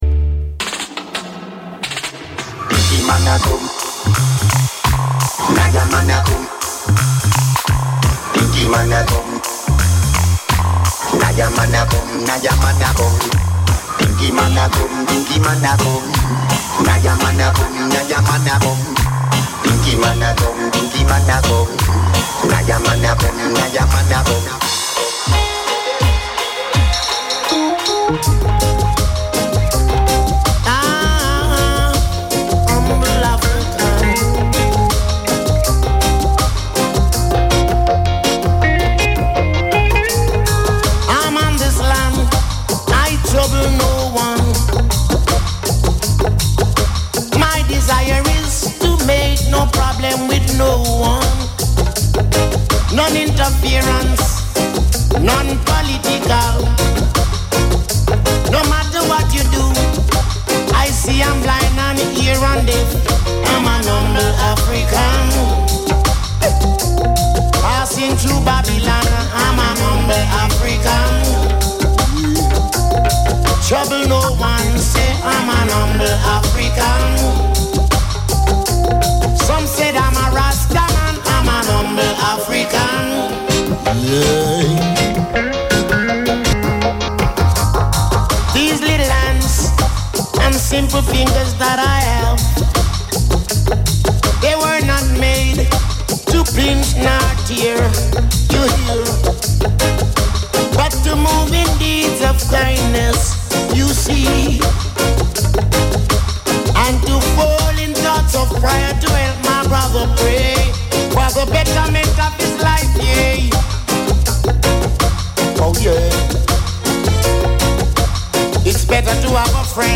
"Bongoman" è una trasmissione radiofonica che va in onda su Controradio.